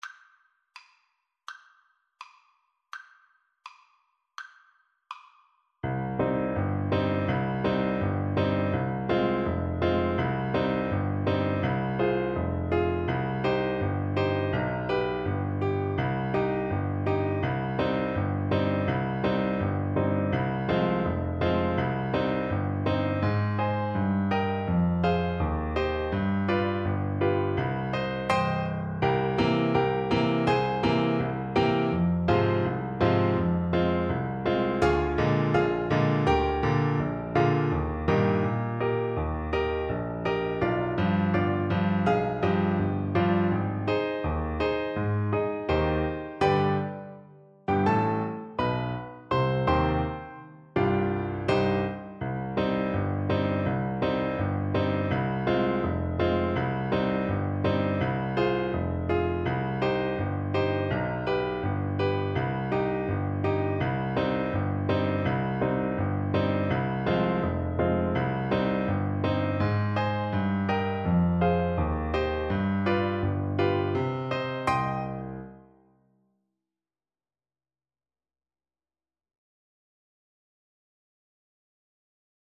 Play (or use space bar on your keyboard) Pause Music Playalong - Piano Accompaniment Playalong Band Accompaniment not yet available transpose reset tempo print settings full screen
French Horn
C minor (Sounding Pitch) G minor (French Horn in F) (View more C minor Music for French Horn )
Allegro (View more music marked Allegro)
2/4 (View more 2/4 Music)
Classical (View more Classical French Horn Music)